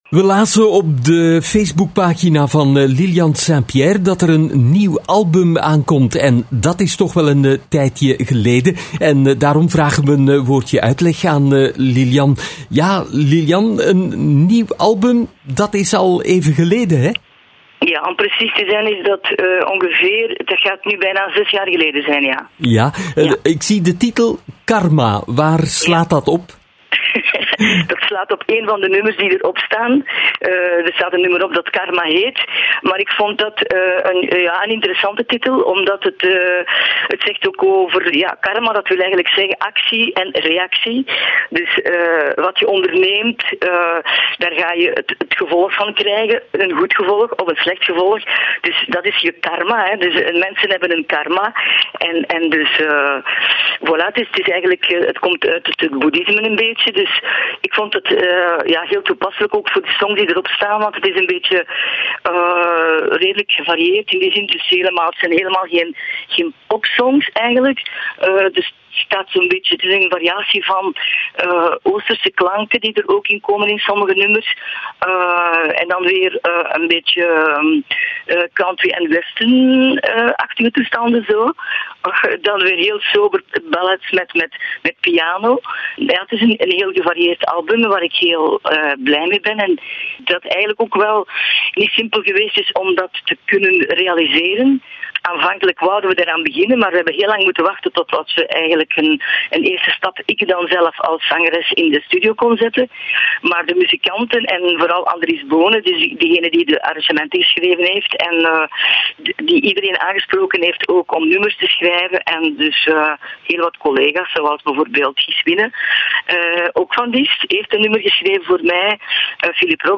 Beluister hieronder het volledig interview met Liliane Saint-Pierre.